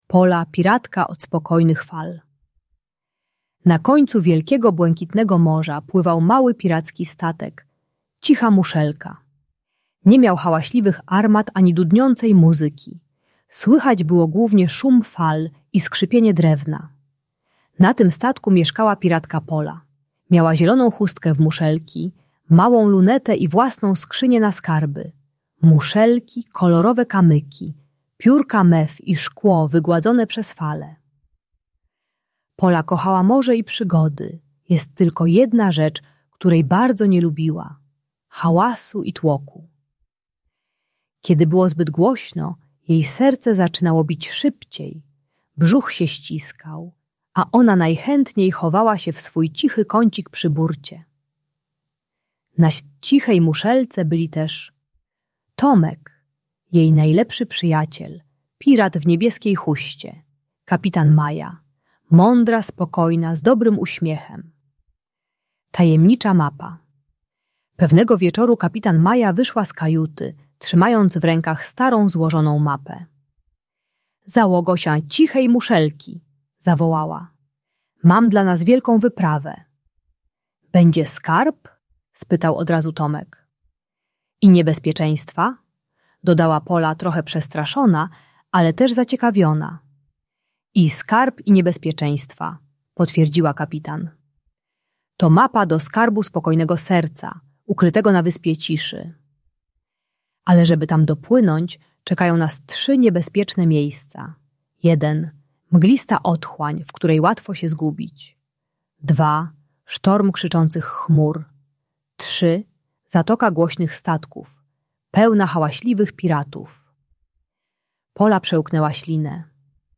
Pola, Piratka od Spokojnych Fal - Lęk wycofanie | Audiobajka